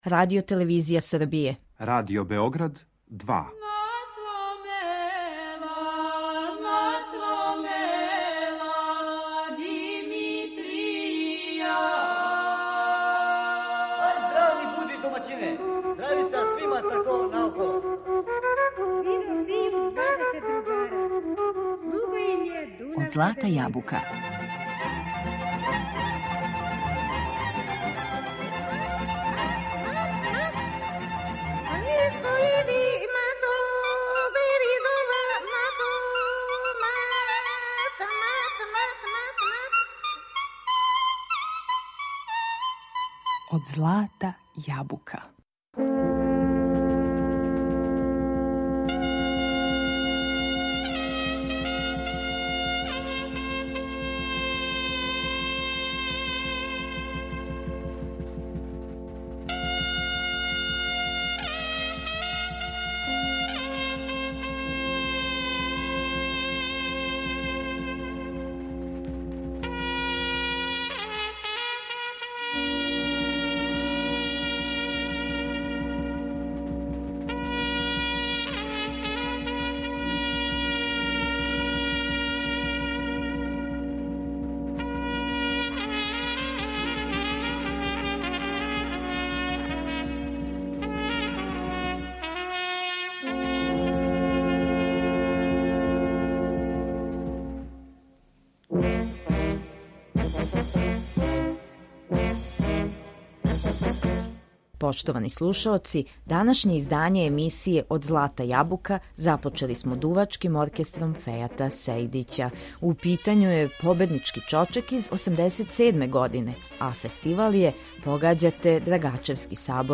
Слушаћемо кола и песме које су симболи одређеног краја у Србији.